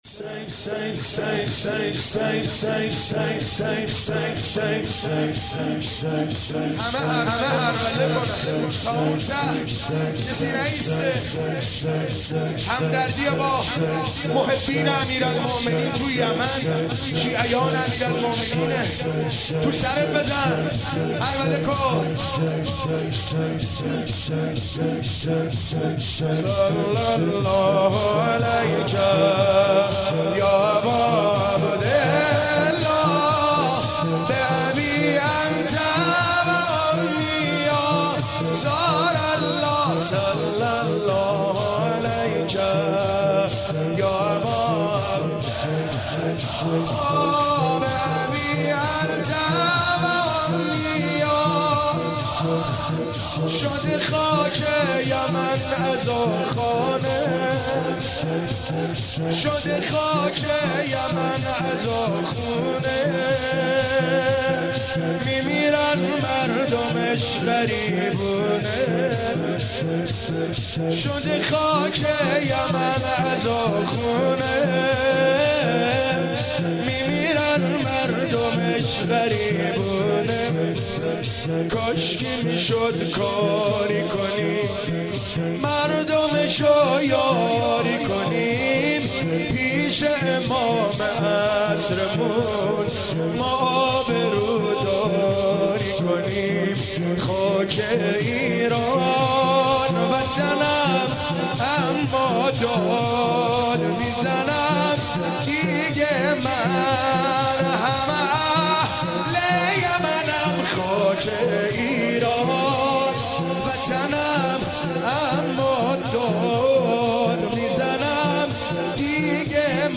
همنوایی